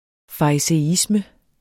Udtale [ fɑisεˈismə ]